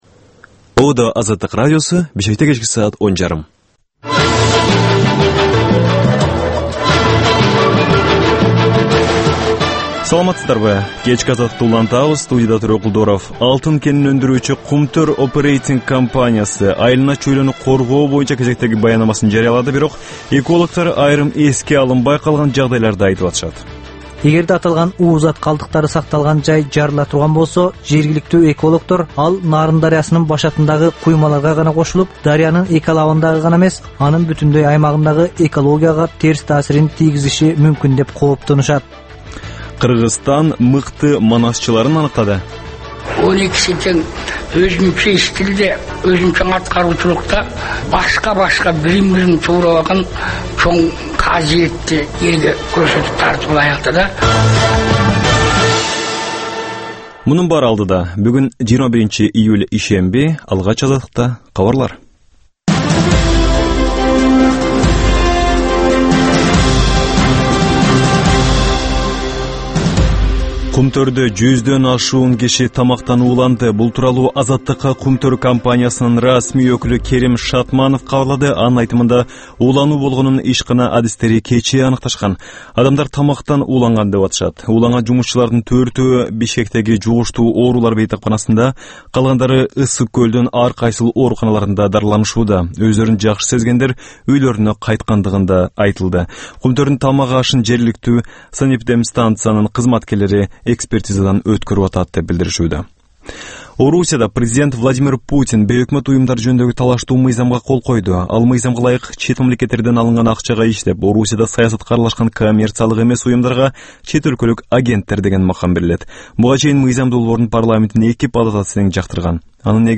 "Азаттык үналгысынын" бул кечки жарым сааттык экинчи берүүсү «Арай көз чарай» түрмөгүнүн алкагындагы тегерек үстөл баарлашуусу, репортаж, маек, талкуу, аналитикалык баян, сереп, угармандардын ой-пикирлери, окурмандардын э-кат аркылуу келген пикирлеринин жалпыламасы жана башка берүүлөрдөн турат.